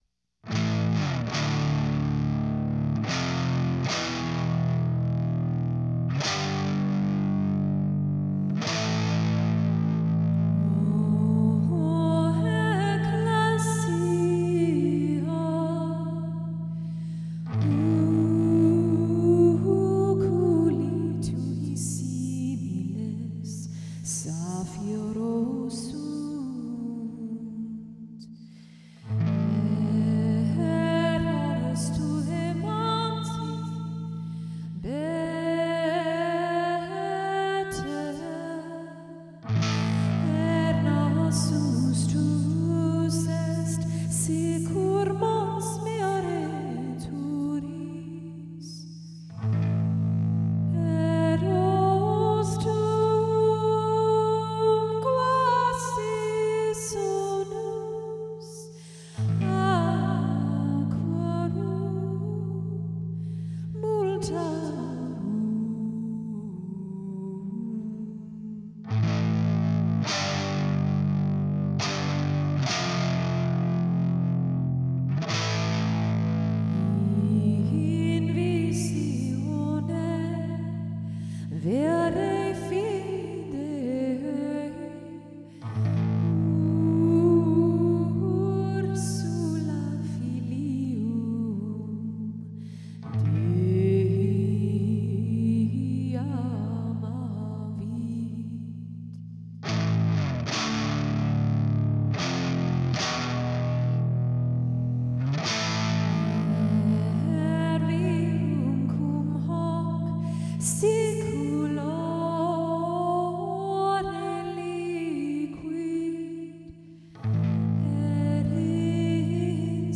A selection from 12th century composition